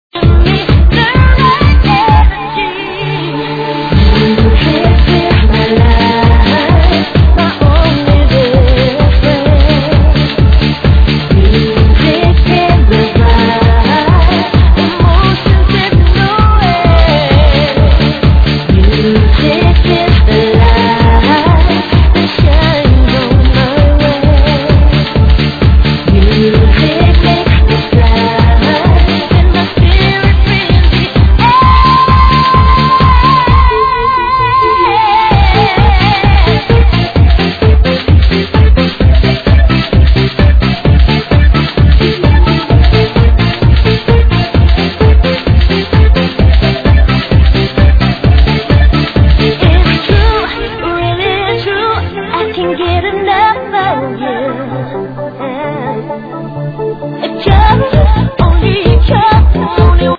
ITs Euro House music (Dance beat with alot of vocals)